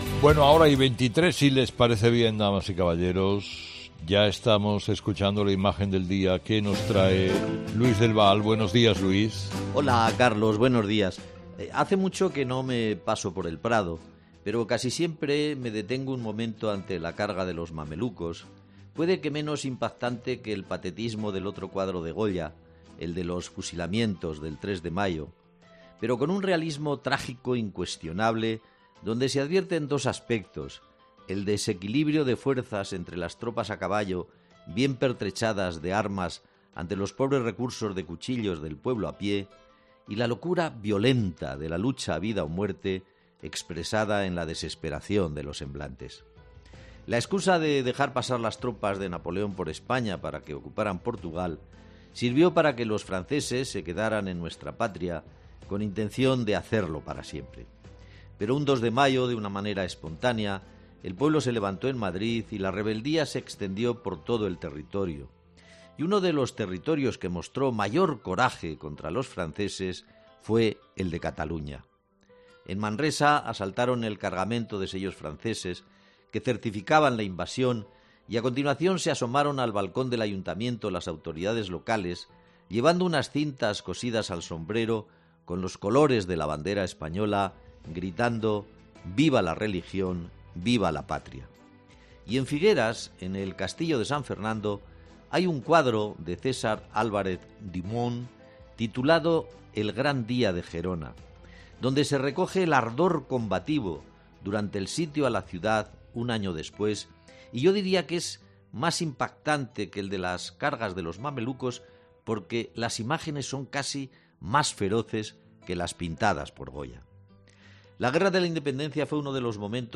Habla Luis del Val en su 'Imagen del día' en 'Herrera en COPE' del Levantamiento del 2 de mayo, día de la Comunidad de Madrid